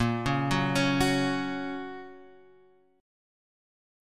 A#m#5 chord